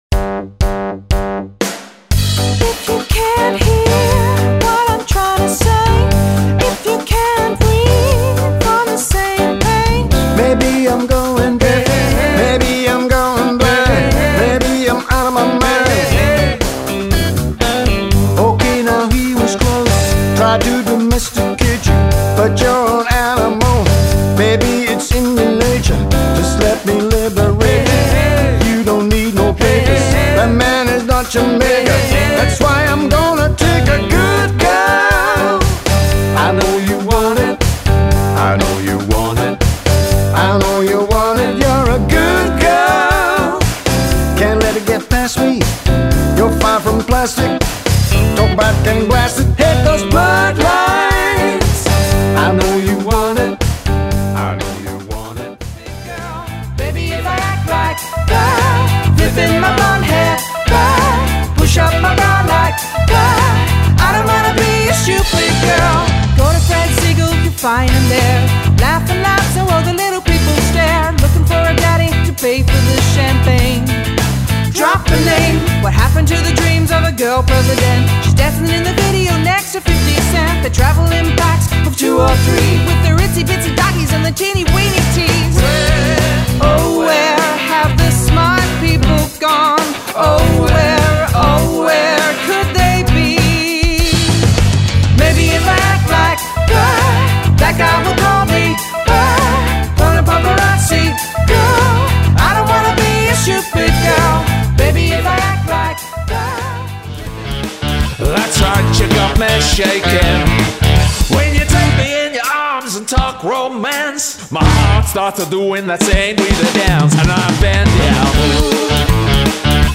Funk, Rock, Soul and Pop grooves.
Contemporary: